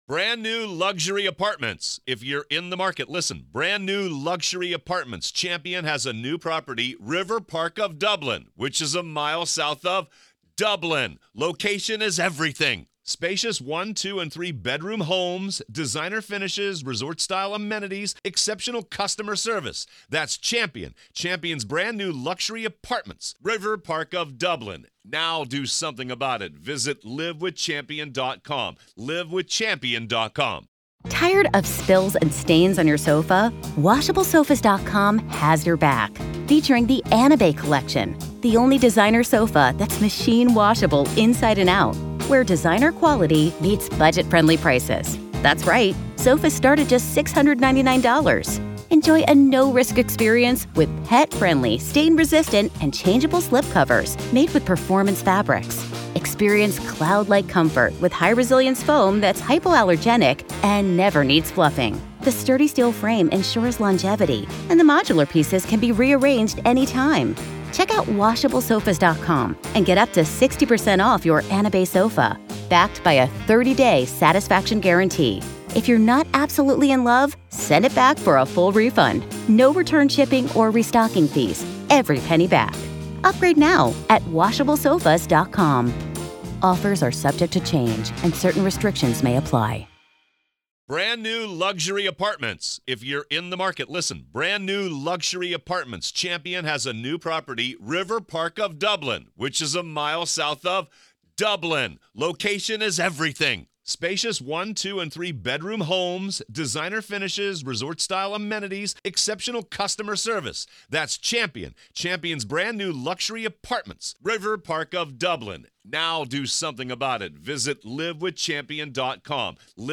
They also delve into the psychological aspects, explaining why certain individuals might be drawn to these more extreme interpretations and practices. Their dialogue illuminates the complex sociological and psychological dynamics that can lead to the creation of cults.